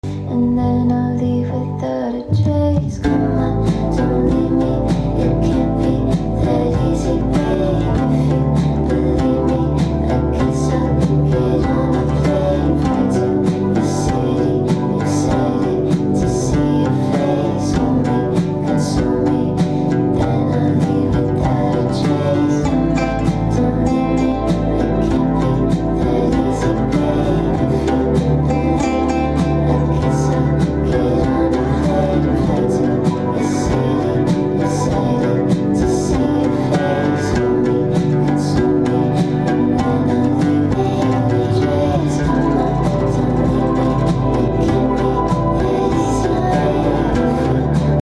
I’m convinced every song needs an Oud